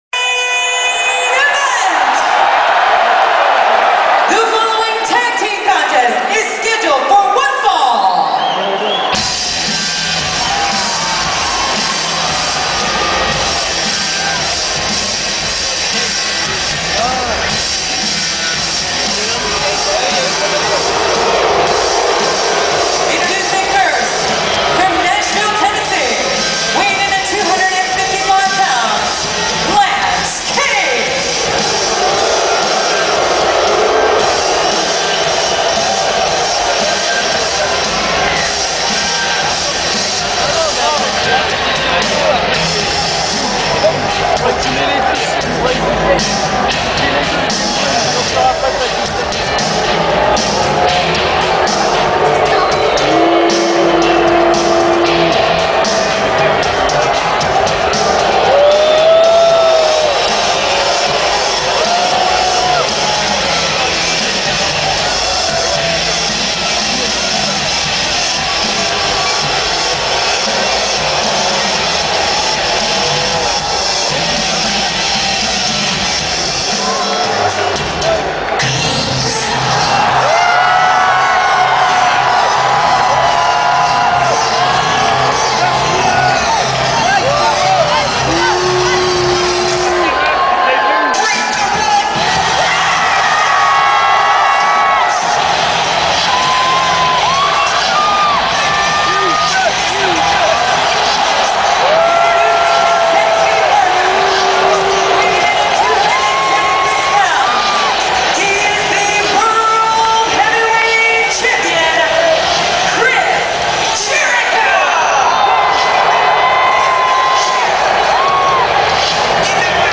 Détail de la soirée qui s'est tenue le 27 septembre 2008 au Palais Omnisport de Paris Bercy.